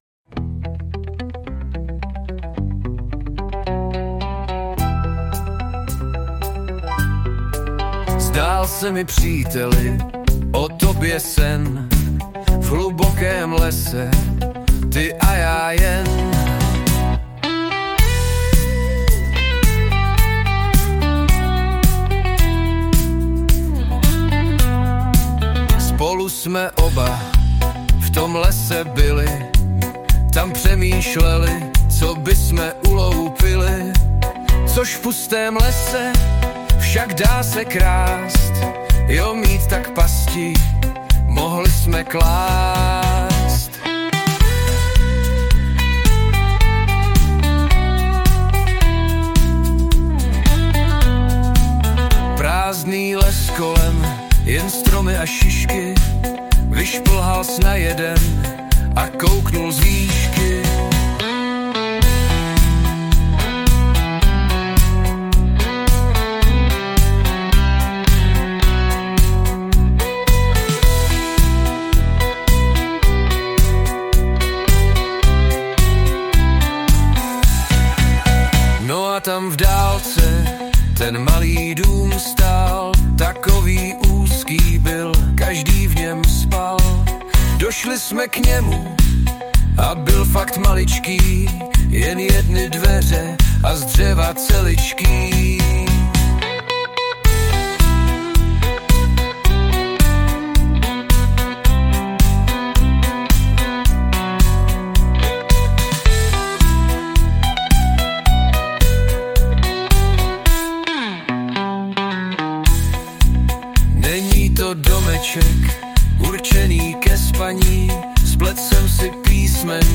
Anotace: Zhudebněno pomoci AI.